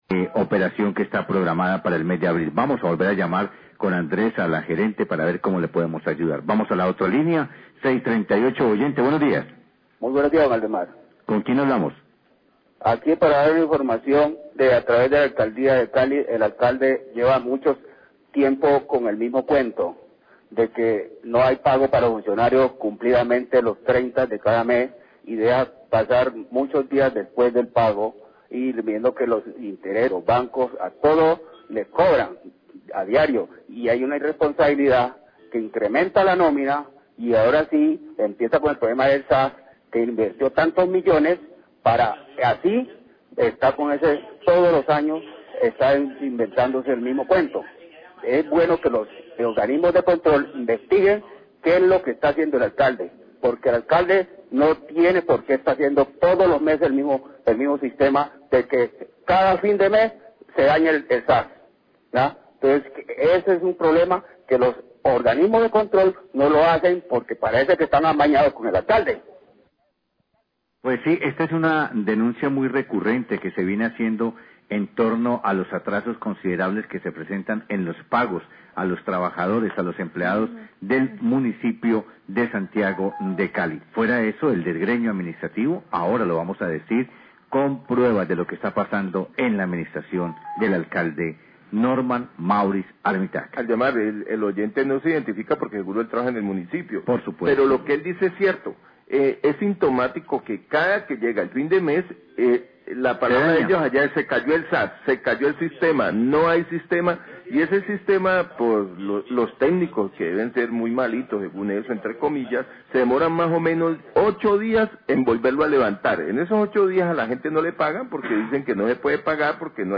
Radio
queja oyente